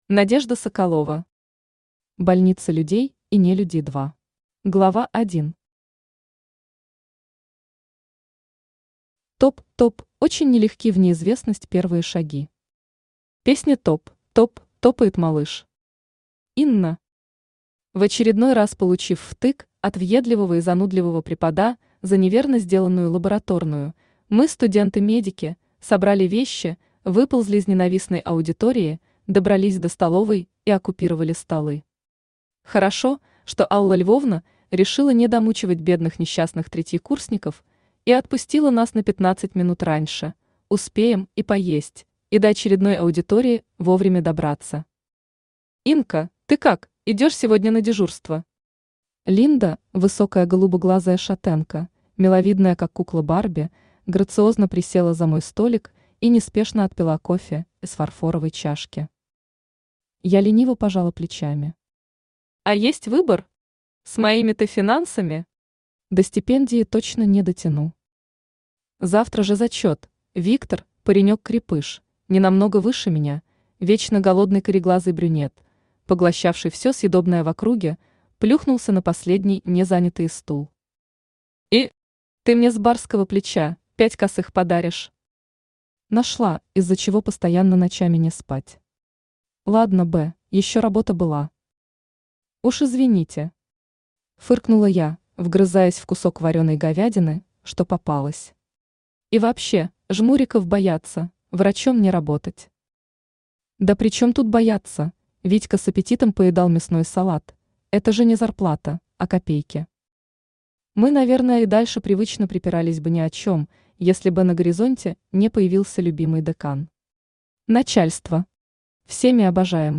Aудиокнига Больница Людей и Нелюдей-2 Автор Надежда Игоревна Соколова Читает аудиокнигу Авточтец ЛитРес.